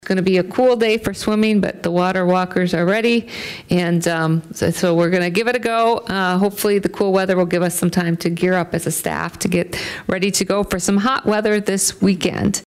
City Manager, Amy Leon, says the aquatic center was originally scheduled to open over Memorial Day weekend, but that has been delayed twice due to colder weather.